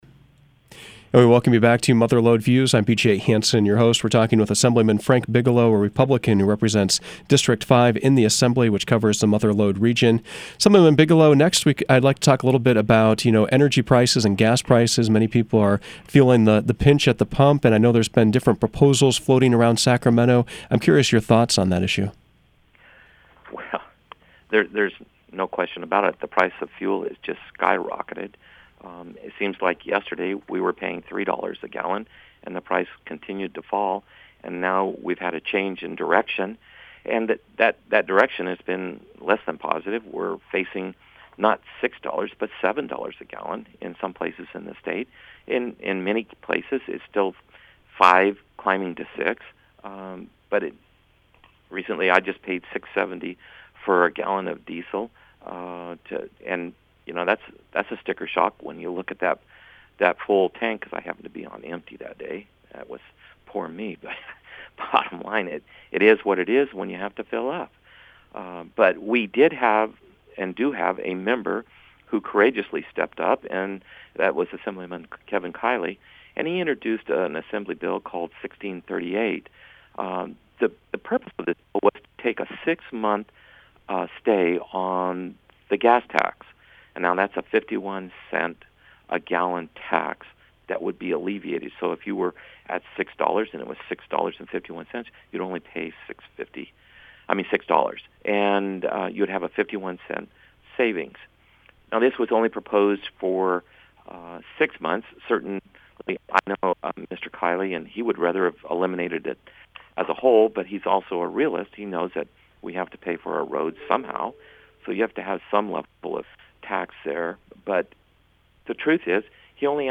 Mother Lode Views featured District Five Republican Assemblyman Frank Bigelow. Some of the topics included a proposal to create a new fire training center in Columbia, Governor Newsom’s budget proposal, the state of the regional economy, water legislation, and his decision not to seek re-election.